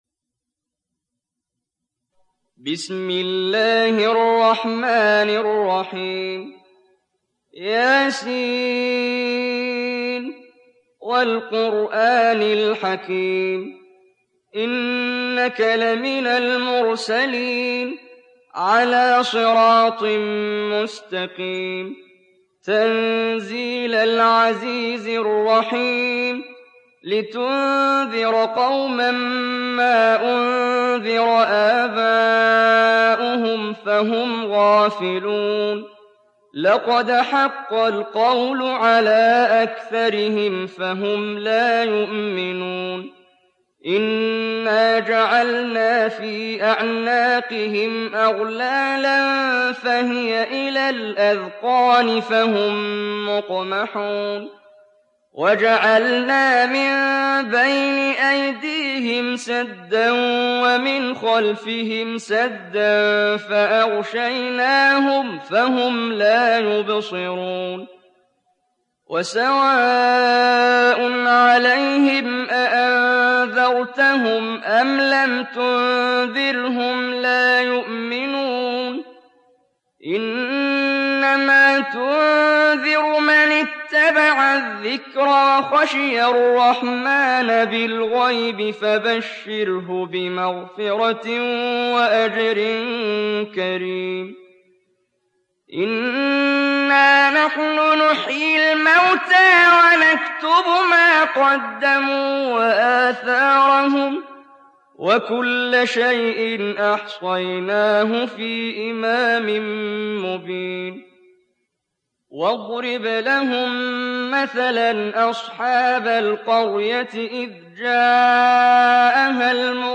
Surah Yasin mp3 Download Muhammad Jibreel (Riwayat Hafs)